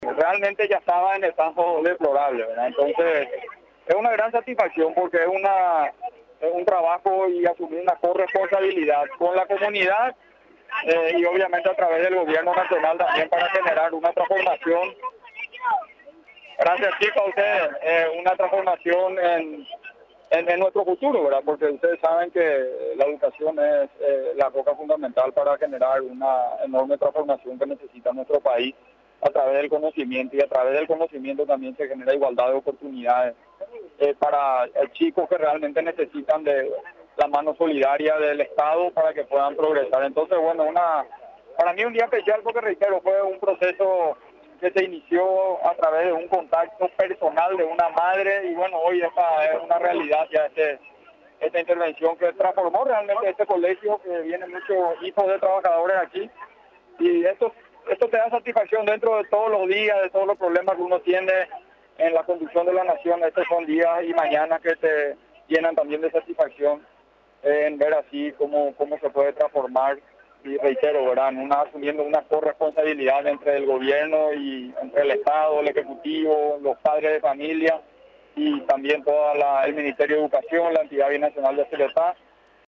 El mandatario paraguayo, Mario Abdo Benítez, habilitó este miércoles las mejoras realizadas en las escuelas Delia Frutos de González, donde también funciona en horario nocturno, el colegio nacional San Vicente de Paul.
40-PDTE.-MARIO-ABDO-1.mp3